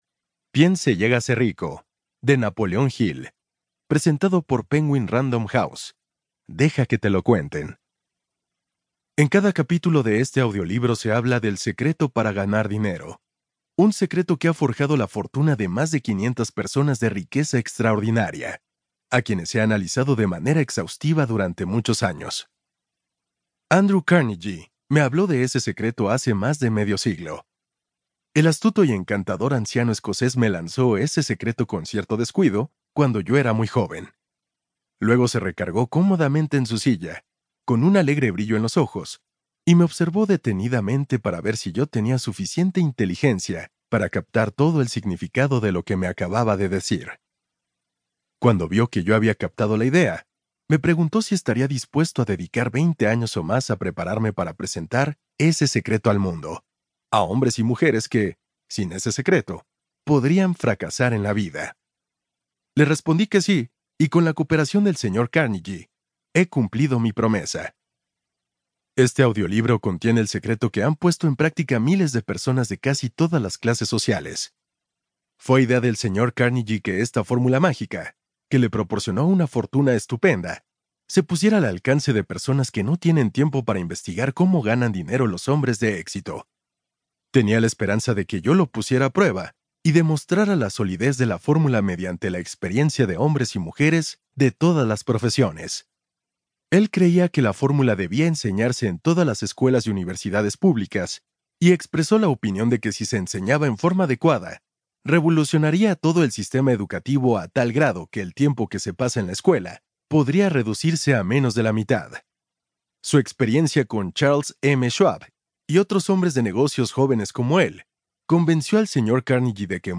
👇 MIRA LA LISTA COMPLETA Y ESCUCHA LA MUESTRA DE CADA AUDIOLiBRO 👇